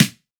• Short Acoustic Snare Sound A Key 351.wav
Royality free steel snare drum sound tuned to the A note. Loudest frequency: 2434Hz
short-acoustic-snare-sound-a-key-351-gHR.wav